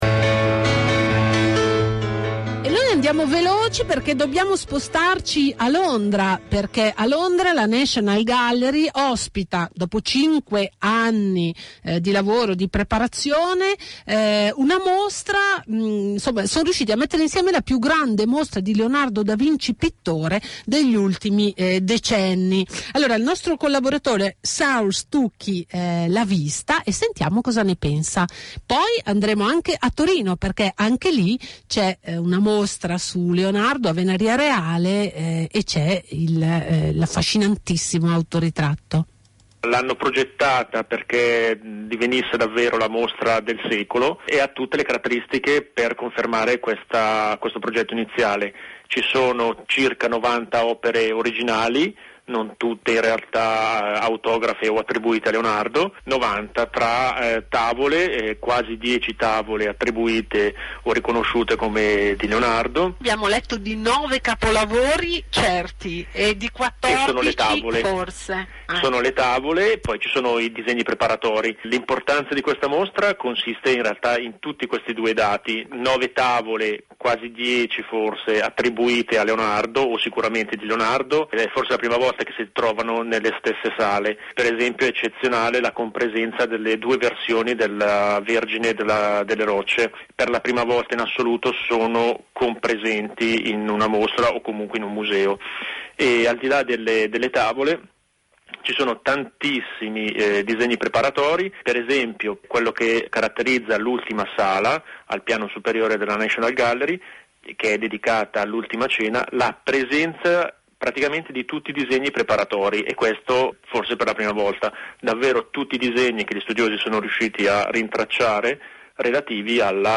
è intervenuto telefonicamente alla trasmissione di Radio Popolare I girasoli